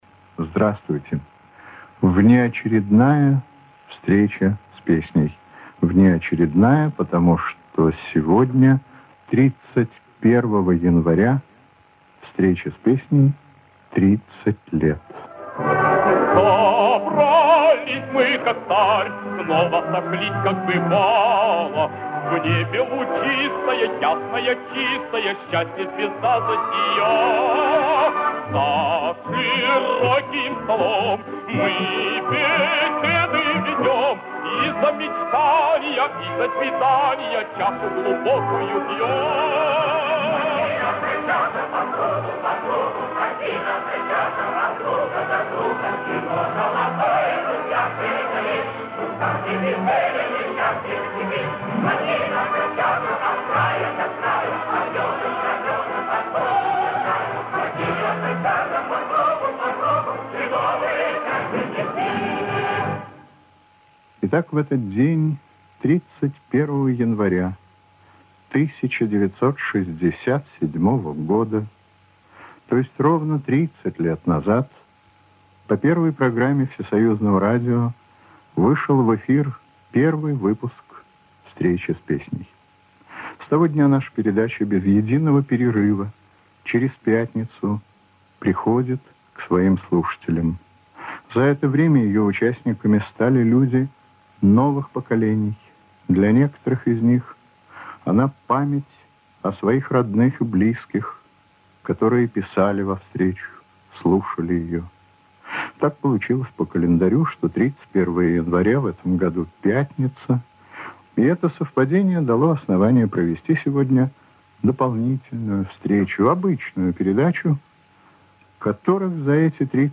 Внеочередной выпуск, посвящённый 30-летию Встречи с песней. Запись на компакт-кассету.